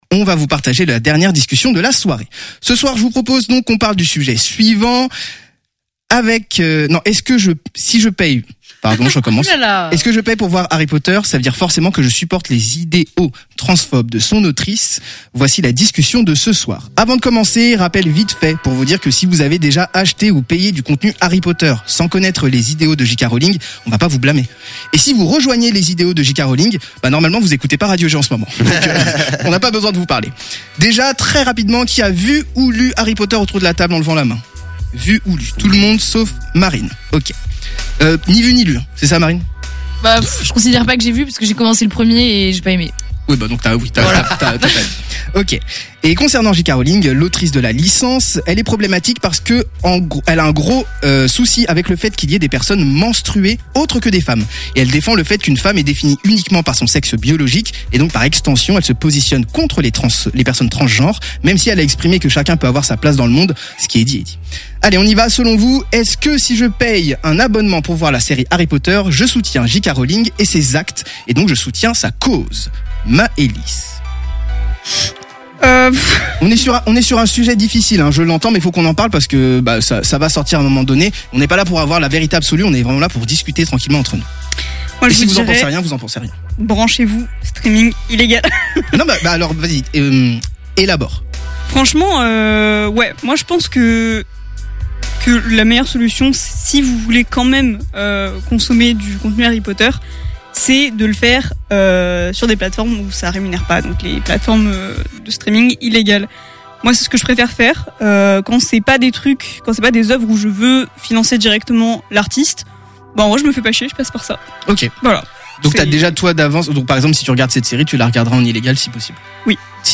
Débat - G!